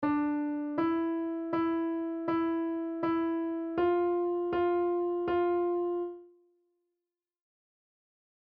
On the piano, play The Grand Old Duke Of York